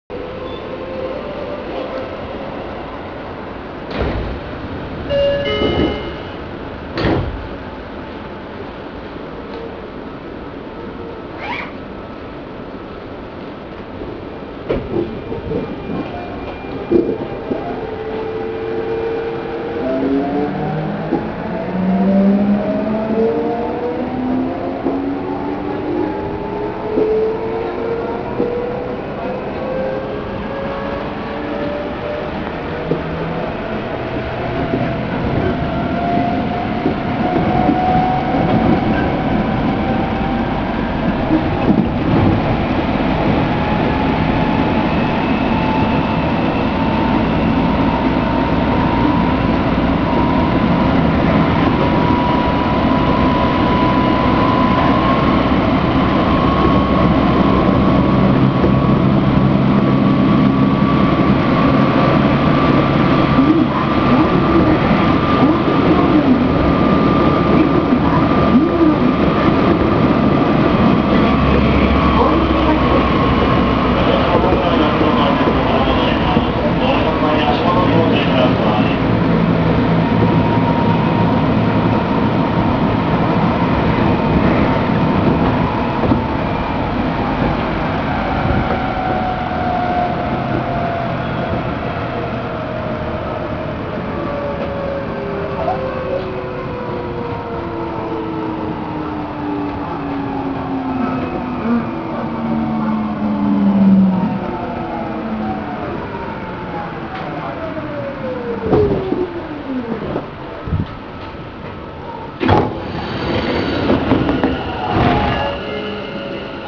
・6000系(6050番台)走行音
【池袋線】保谷→大泉学園（1分56秒：634KB）
当然と言えば当然なのかもしれませんが、6000番台と全く変わりません。
ドアチャイムのずれも相変わらずです。